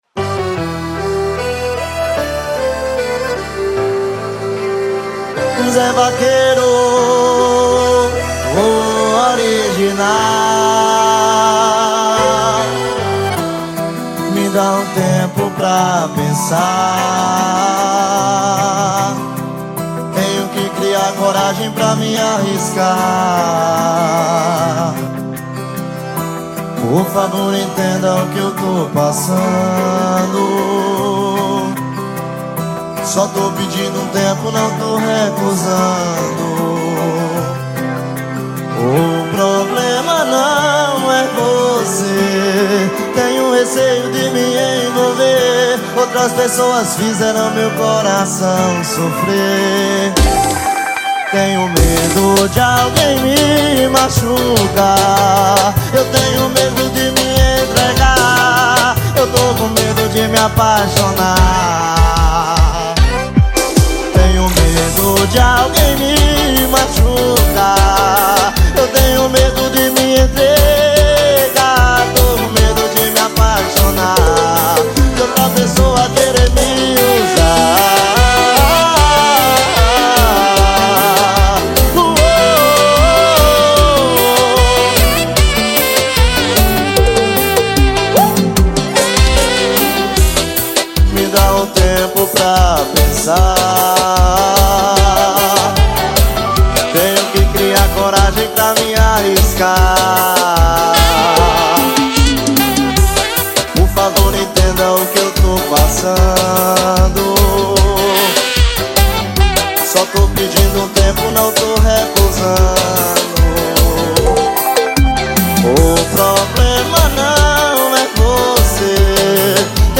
2024-09-14 03:33:49 Gênero: Forró Views